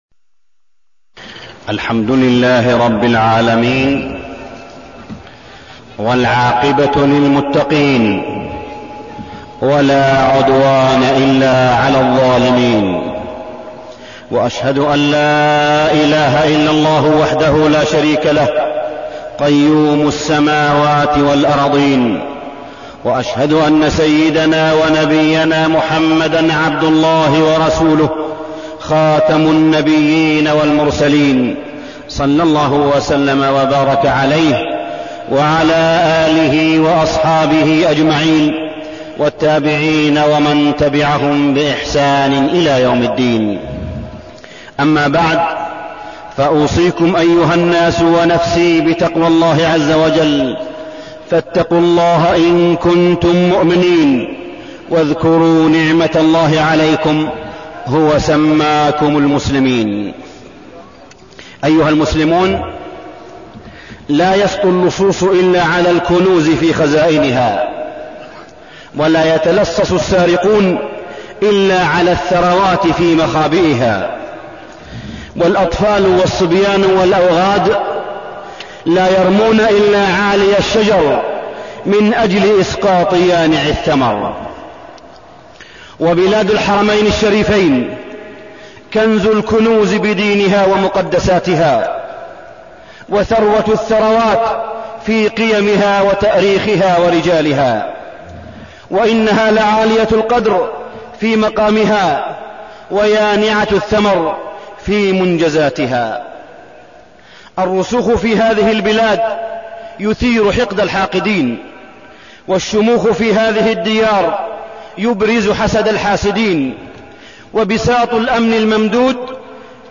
تاريخ النشر ١٢ صفر ١٤١٧ هـ المكان: المسجد الحرام الشيخ: معالي الشيخ أ.د. صالح بن عبدالله بن حميد معالي الشيخ أ.د. صالح بن عبدالله بن حميد حوادث التفجير والإرهاب